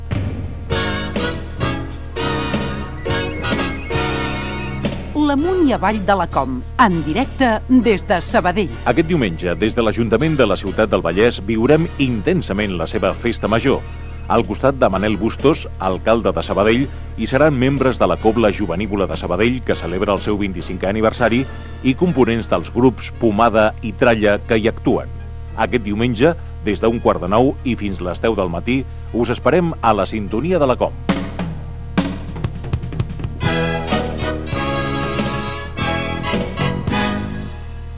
Promoció del programa especial des de Sabadell amb motiu de la seva festa major